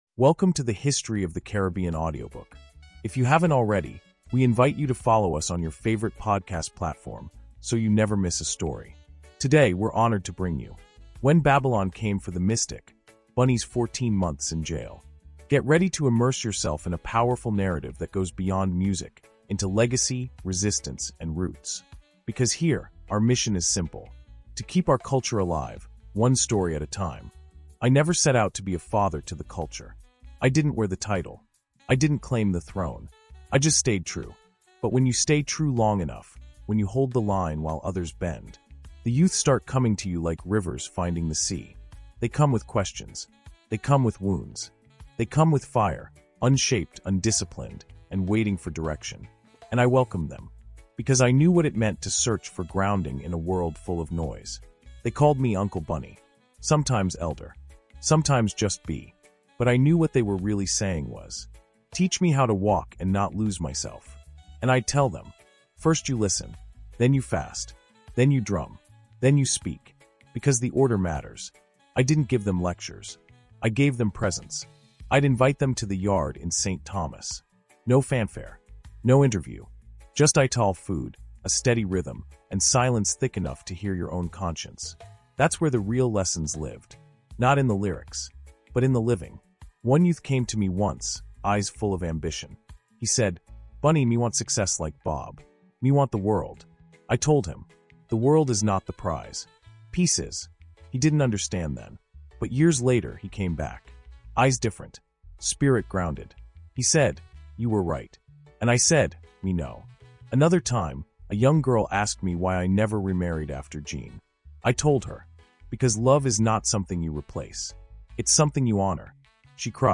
Children of the Drum – Bunny’s Role as a Cultural Father Figure | Audiobook Insight